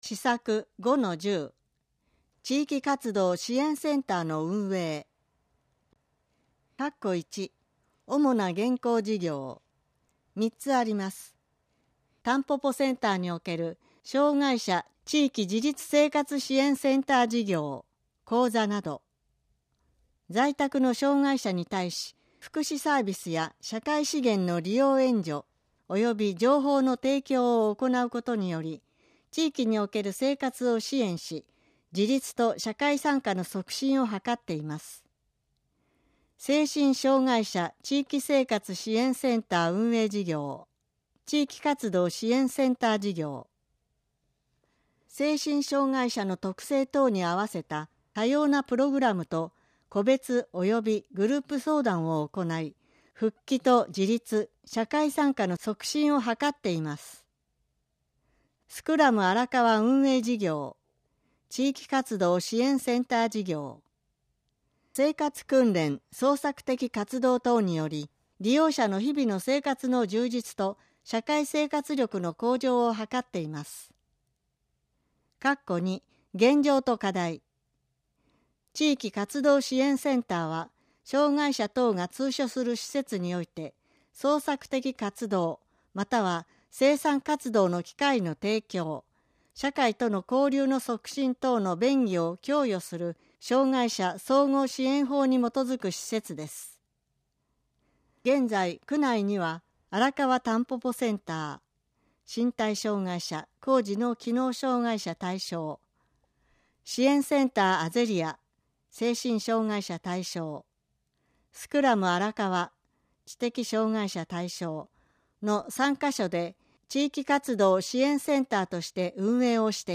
計画の音声版（デイジー版）CDを区役所の障害者福祉課及び区立図書館で貸出しています。